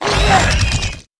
naga_commander_attack.wav